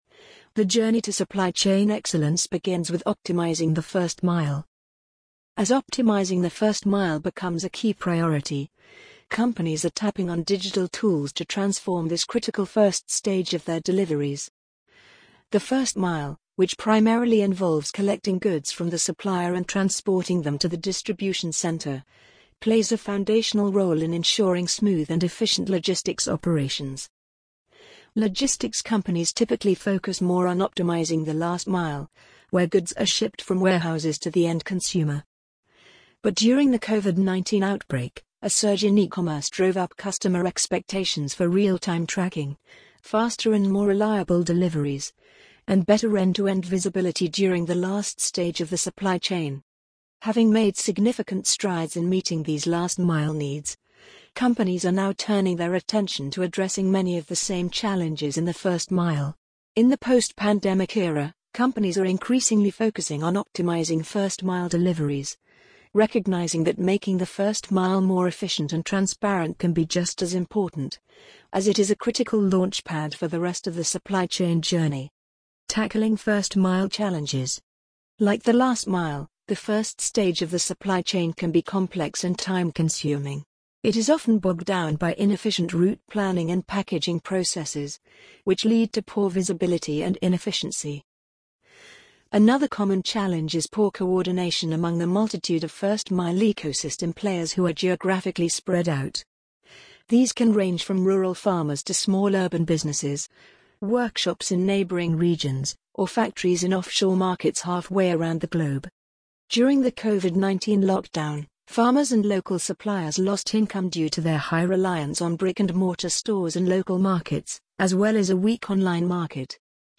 amazon_polly_46809.mp3